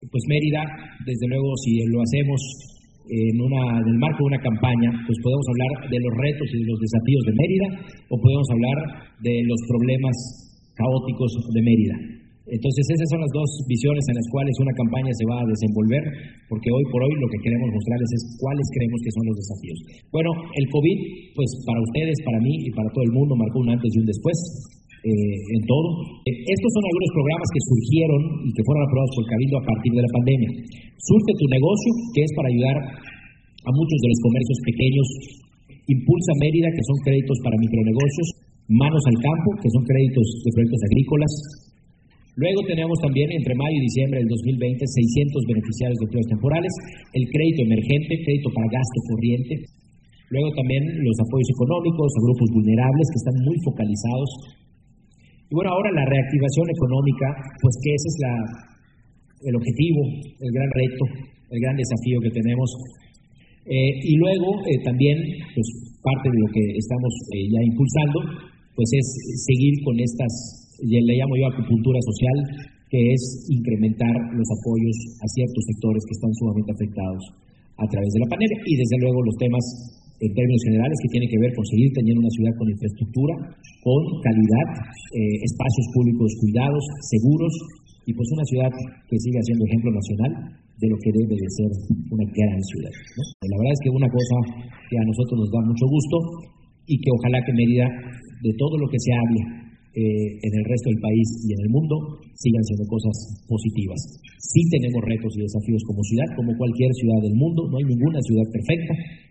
Renán Barrera Concha, candidato del PAN a la alcaldía de Mérida, se reunió este día con integrantes de la Asociación de Sociedades Financieras de Objeto Múltiple en México, A.C. (Asofom) región Sureste
renan-barrera-concha-mensaje-asofom.mp3